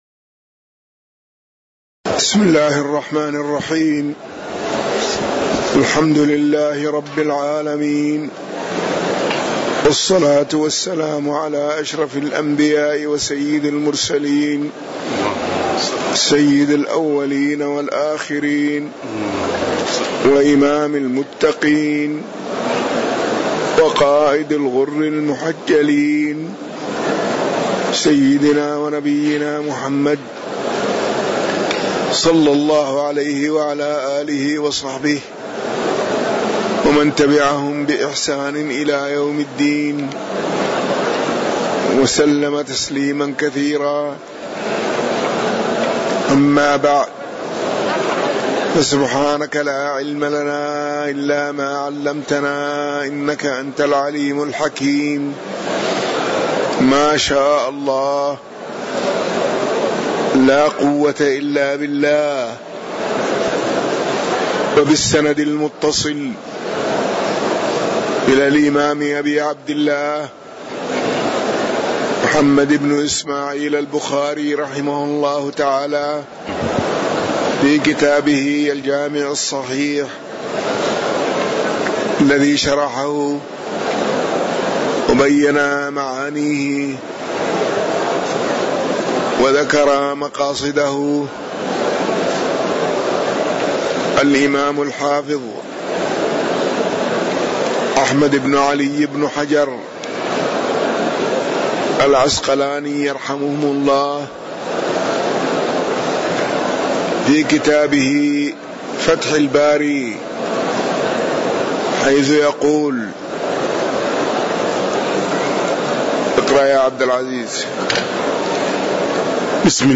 تاريخ النشر ٢٧ جمادى الأولى ١٤٣٩ هـ المكان: المسجد النبوي الشيخ